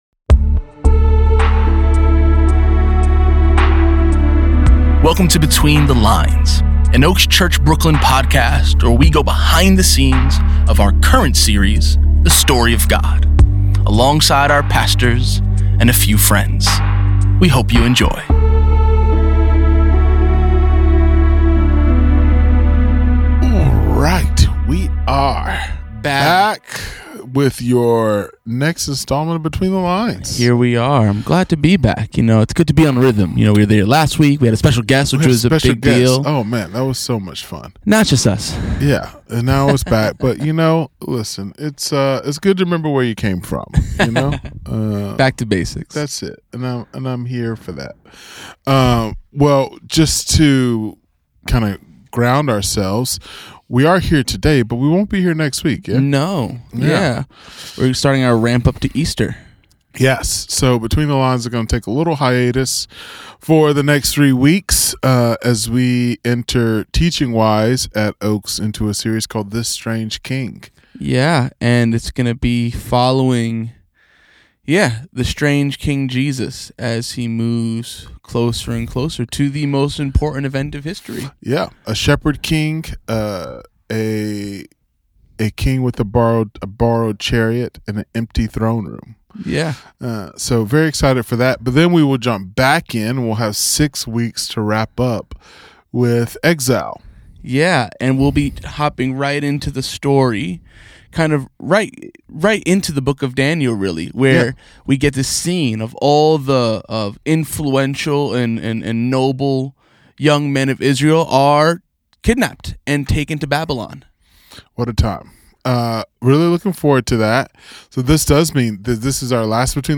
This message coincided with Pentecost Sunday at Oaks where the Spirit was moving powerfully in the gathering.